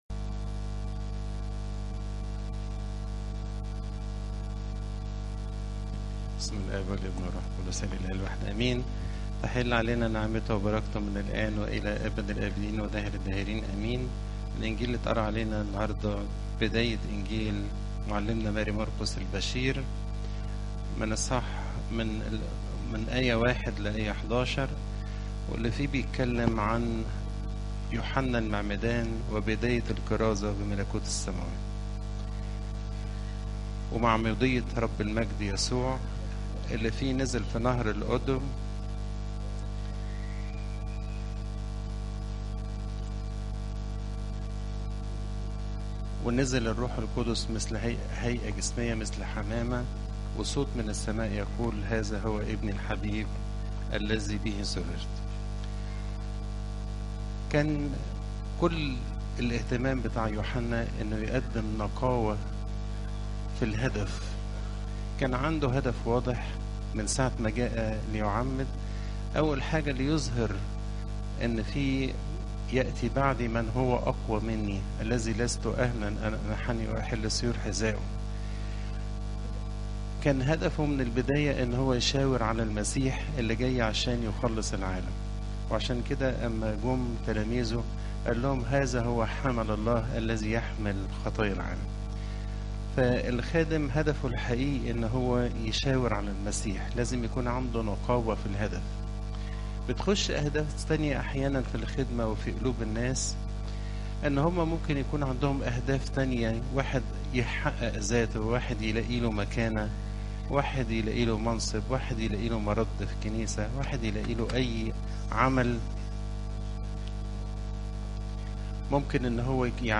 عظات قداسات الكنيسة